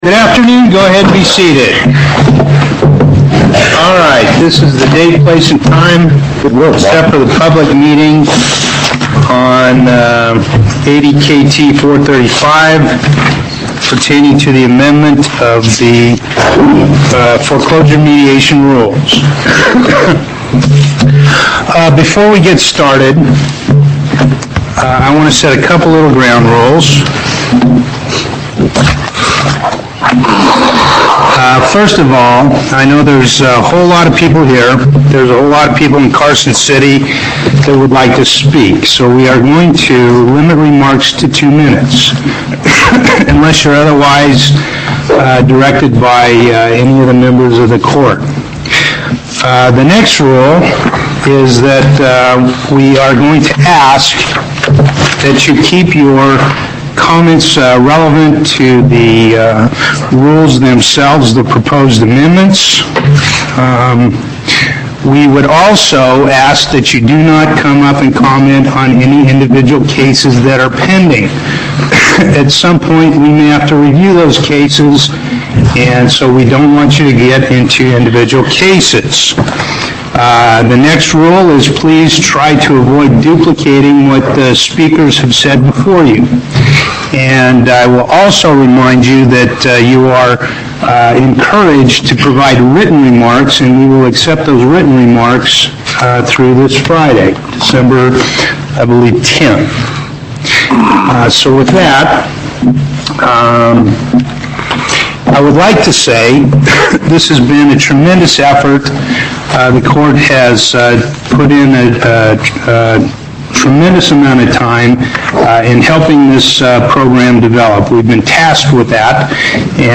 Location: Las Vegas Before the En Banc Court